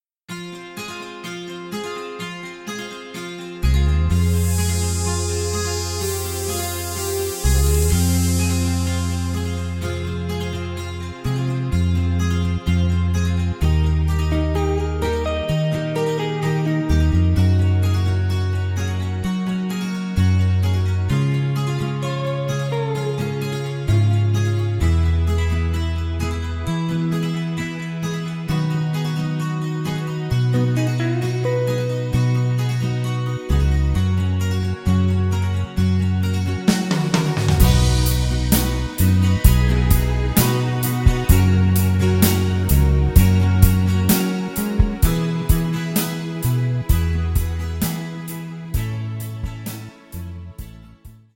avec choeurs